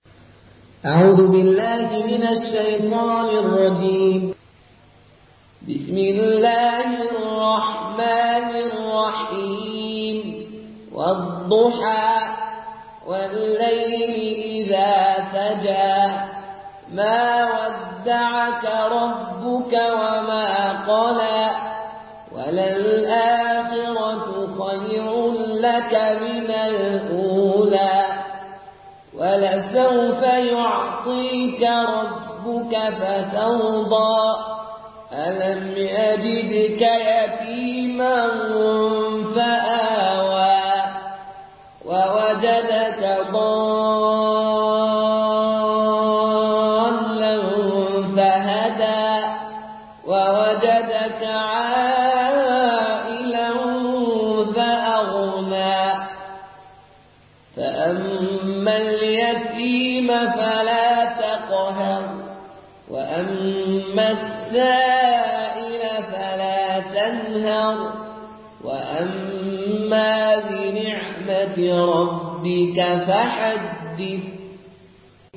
Une récitation touchante et belle des versets coraniques par la narration Qaloon An Nafi.
Murattal Qaloon An Nafi